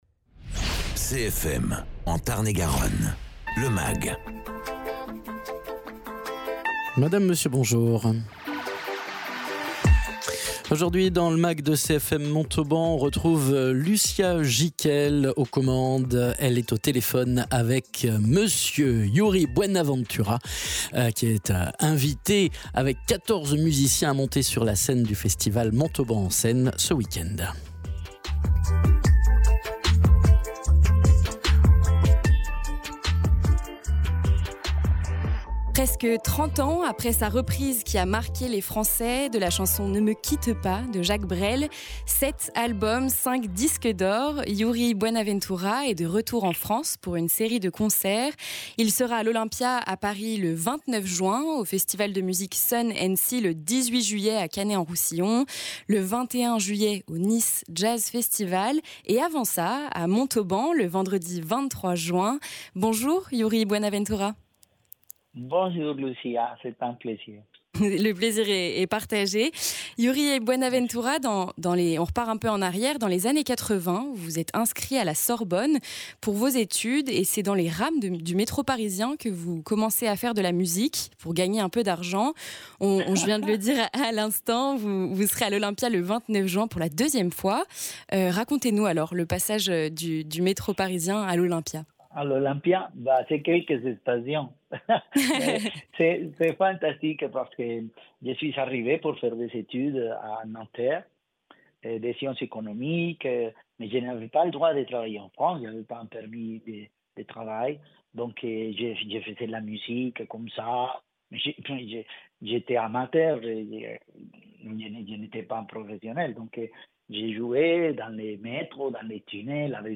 à l’occasion de son passage au festival Montauban en scène, Yuri Buenaventura est dans le magazine au téléphone